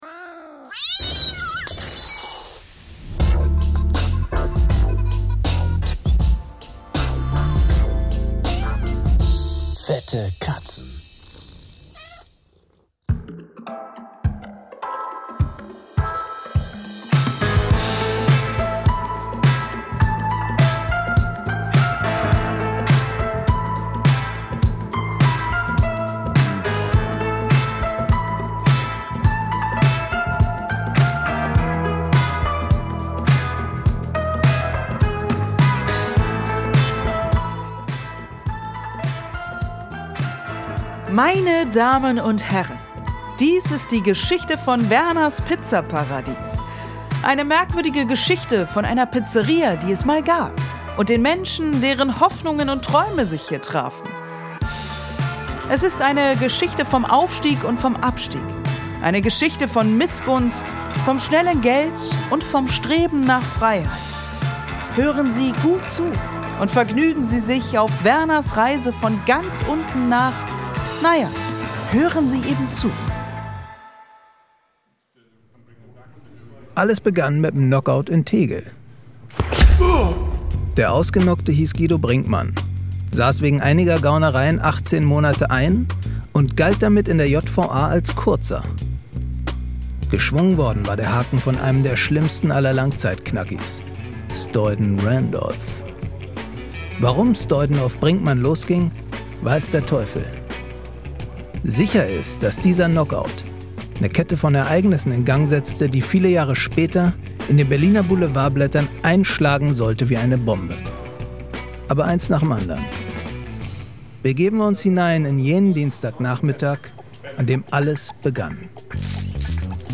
Werner - Oliver Korittke
Stoyden - Kida Khodr Ramadan
Leon - Edin Hasanovic
Ralph Jürgens - Ben Becker
Uwe - Ralf Richter
Strollinger - Winfried Glatzeder